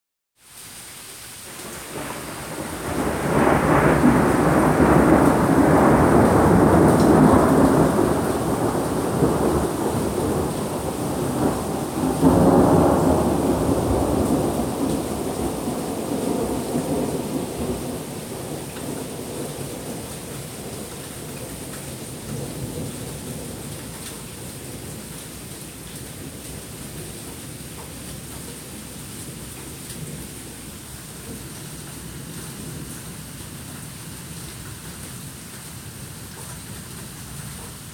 …A summer thunderstorm early one morning last summer.  As you can hear, the rolling thunder just went on forever!